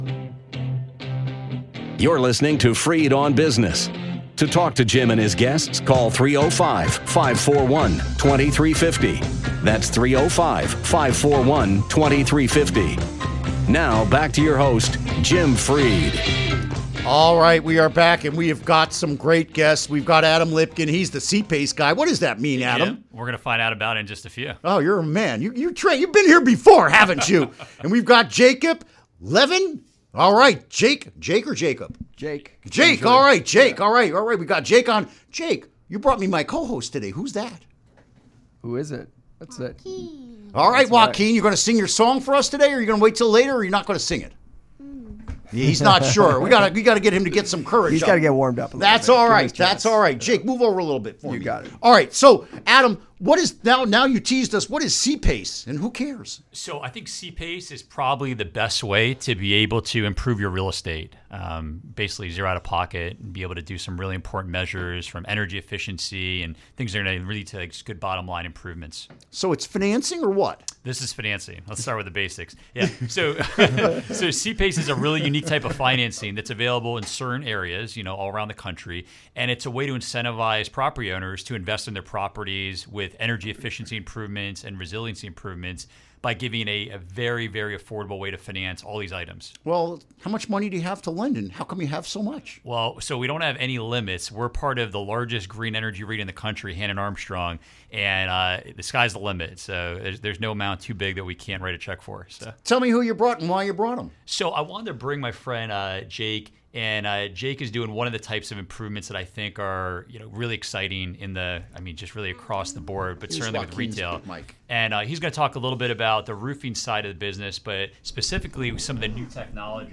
Interview Segment Click here to download (To download, right-click and select “Save Link As”.)